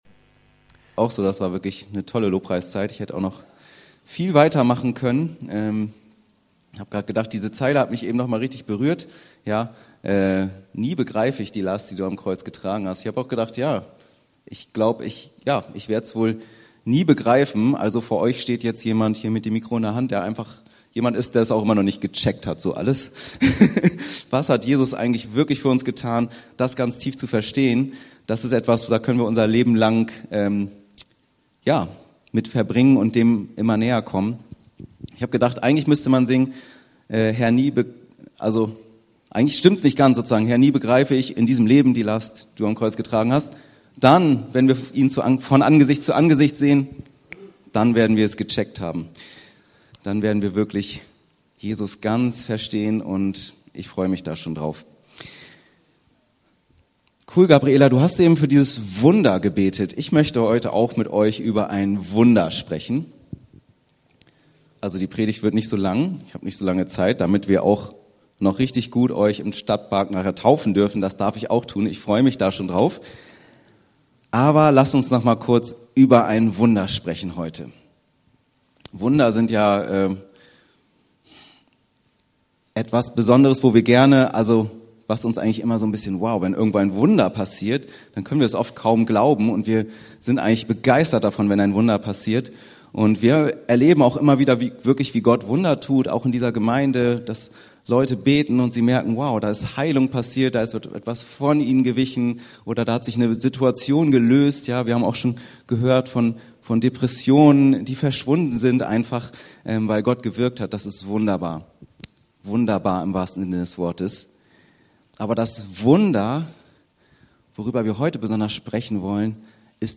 Tauf Gottesdienst: Das Wunder der Umkehr Apg. 2,37ff